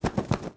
Babushka / audio / sfx / Animals / SFX_Duck_Wings_01.wav
SFX_Duck_Wings_01.wav